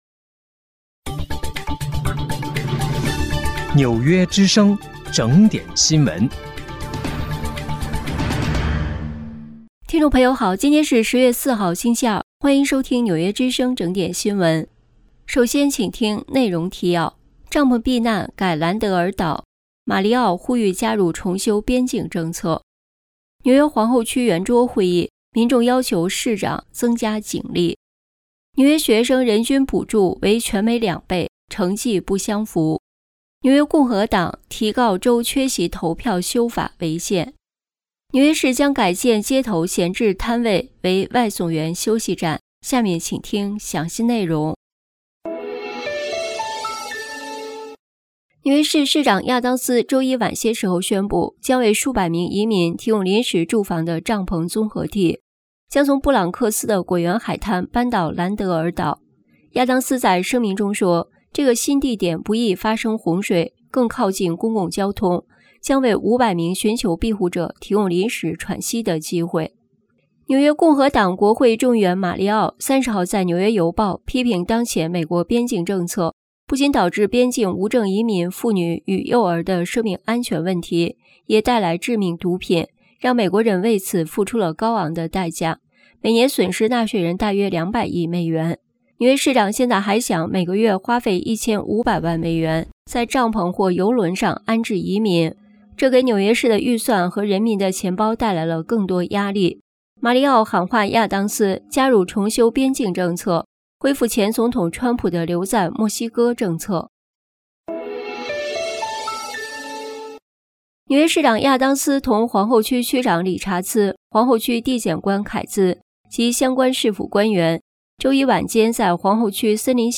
10月4号(星期二)纽约整点新闻